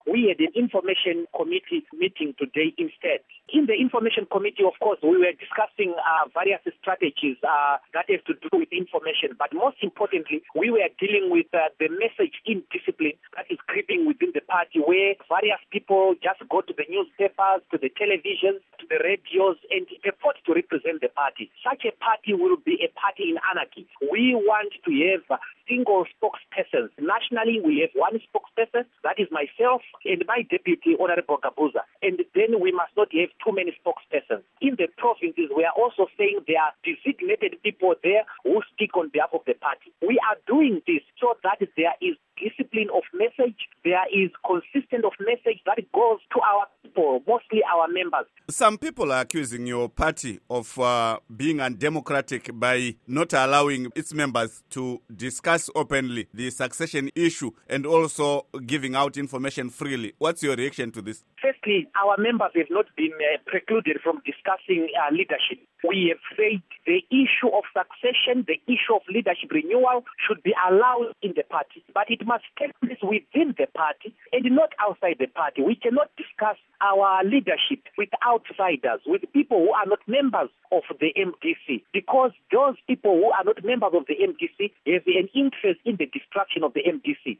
Interview With Douglas Mwonzora